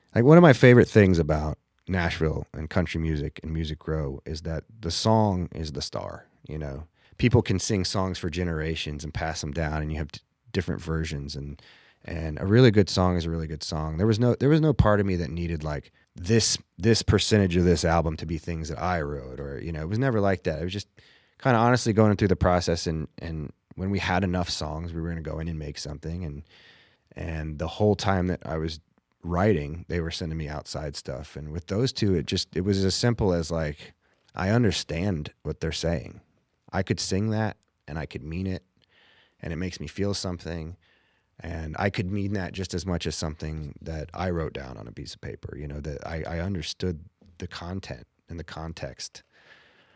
Luke Grimes talks about recording outside songs for his EP.
Luke-Grimes-cutting-outside-songs.mp3